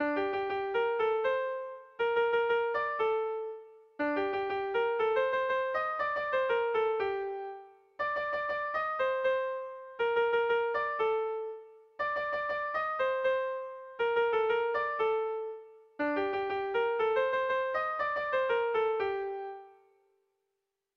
Irrizkoa